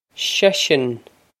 Pronunciation for how to say
shesh-on
This is an approximate phonetic pronunciation of the phrase.